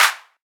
BWB 5 Clap (24).wav